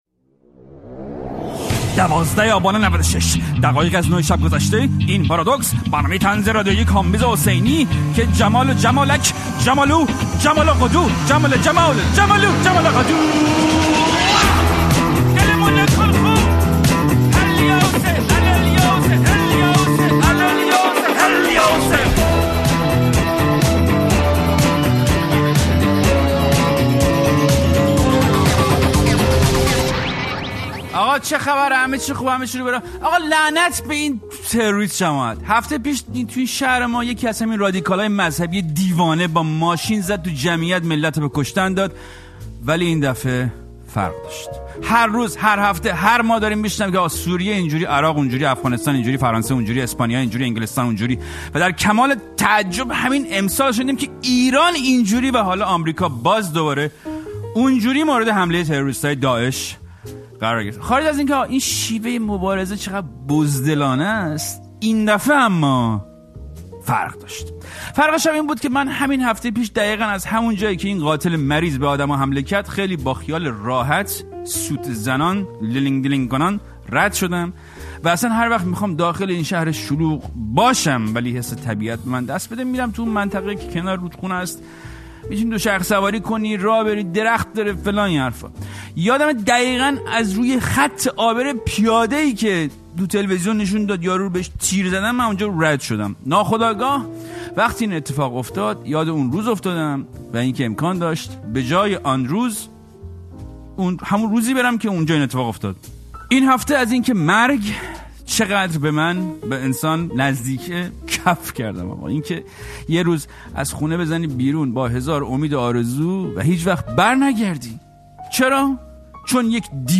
پارادوکس با کامبیز حسینی؛ گفت‌وگو با نیکزاد نجومی